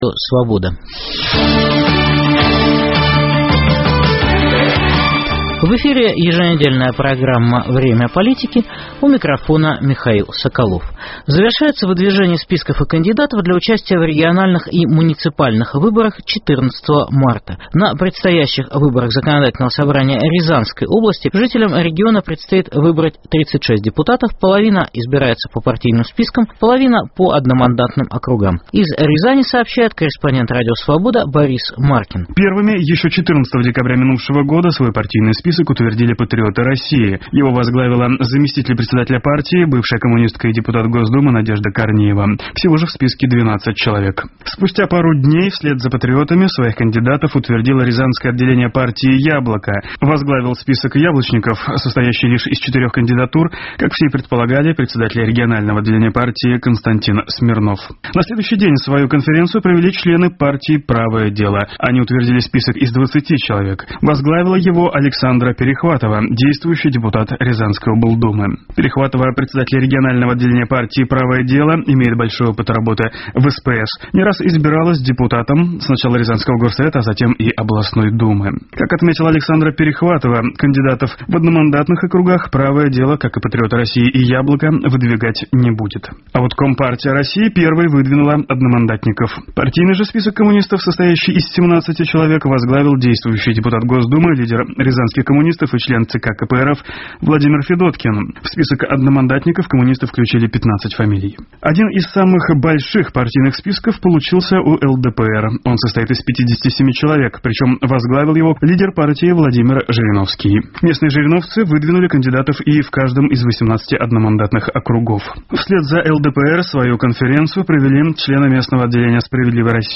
В программе - обзор весенней предвыборной кампании в российских регионах. Репортажи из Хабаровска, Рязани, Орла, Иркутска, Екатеринбурга, Воронежа. Анализ политической ситуации в Республике Алтай.